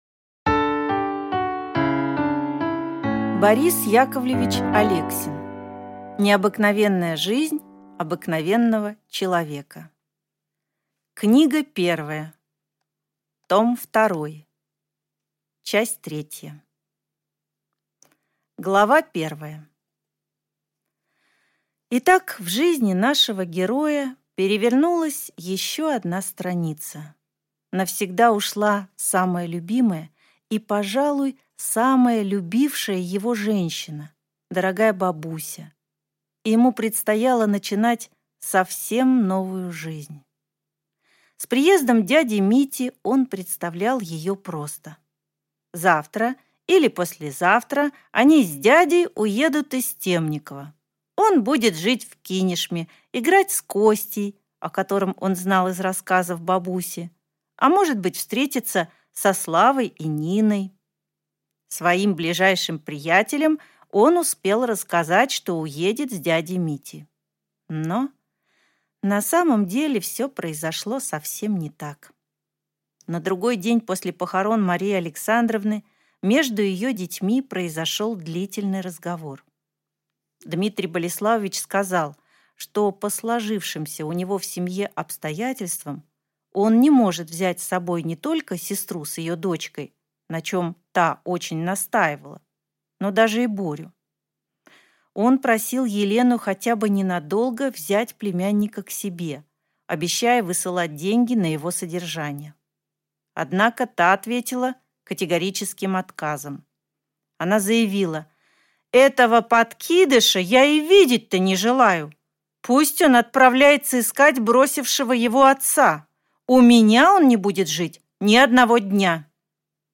Аудиокнига Необыкновенная жизнь обыкновенного человека. Книга 1. Том 2 | Библиотека аудиокниг